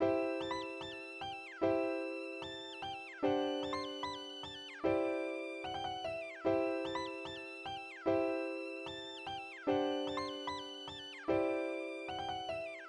Future 149 bpm.wav